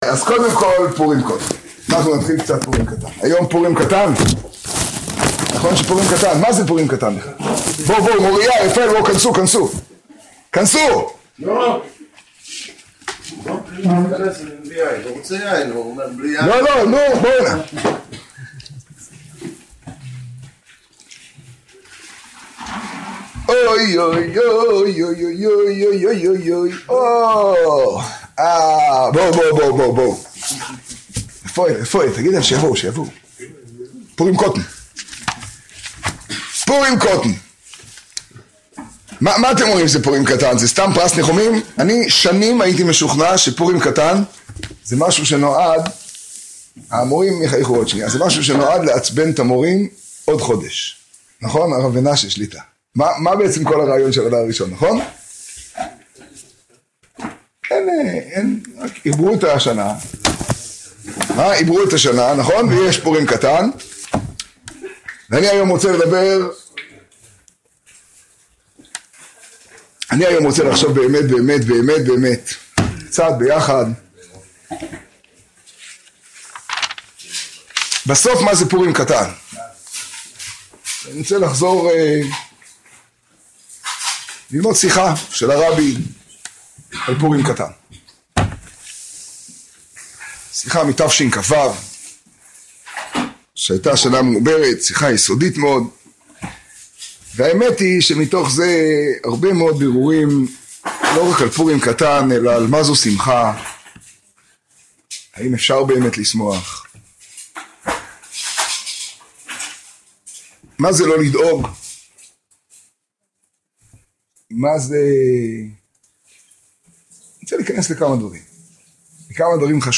השיעור במגדל, פרשת כי תשא תשעד.